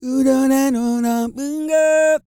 E-CROON 3015.wav